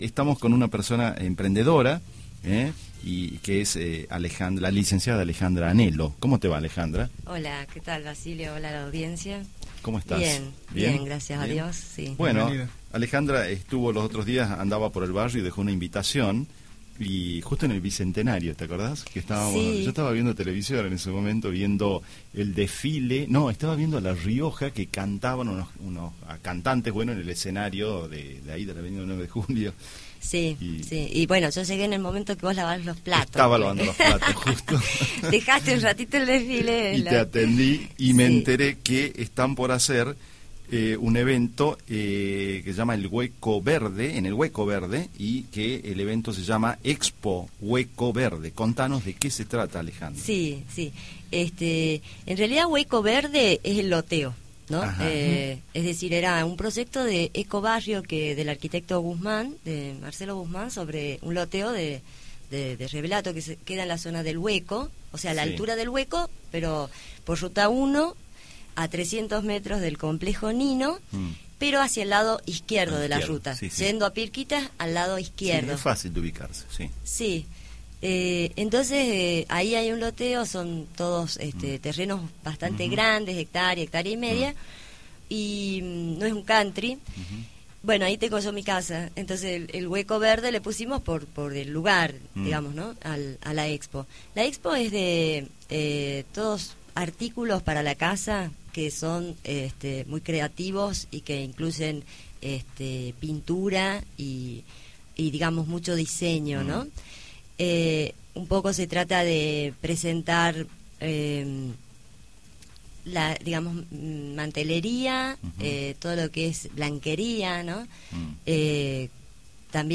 A vos Ciudad. AUDIO DE LA ENTREVISTA